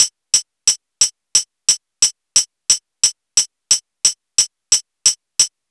Index of /90_sSampleCDs/USB Soundscan vol.02 - Underground Hip Hop [AKAI] 1CD/Partition C/06-89MPC3KIT